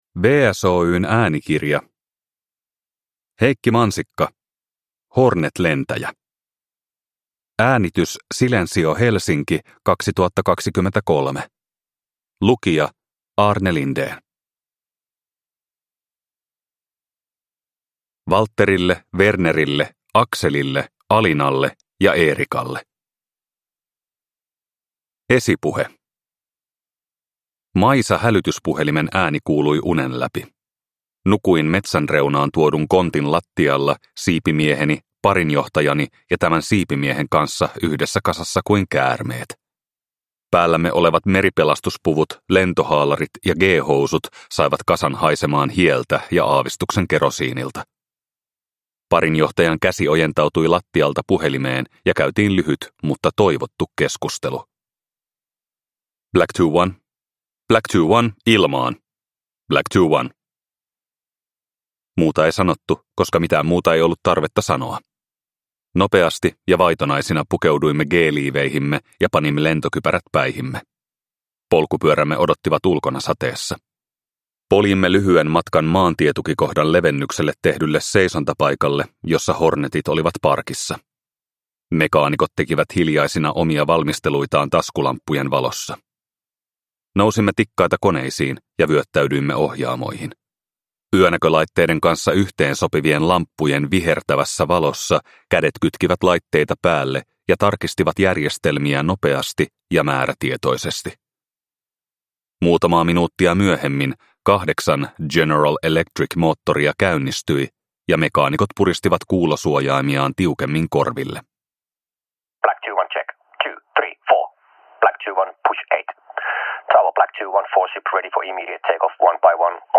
Hornet-lentäjä – Ljudbok – Laddas ner